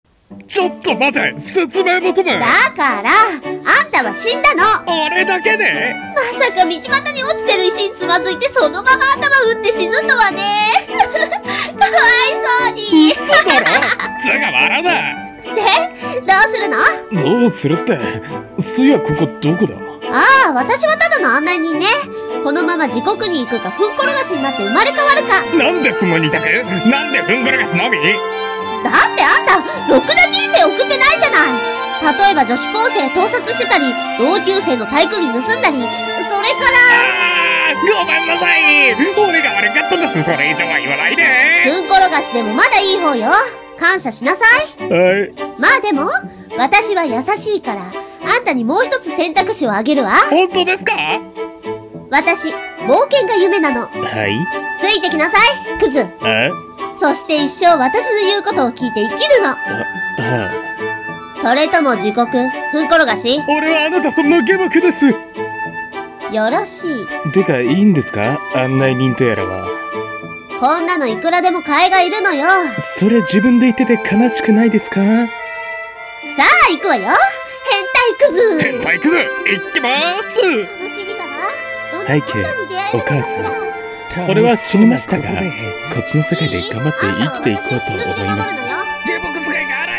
【二人声劇】死んだら何故か謎の案内人と冒険することになった件